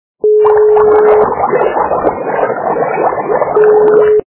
При прослушивании Шум - Кипящей воды качество понижено и присутствуют гудки.
Звук Шум - Кипящей воды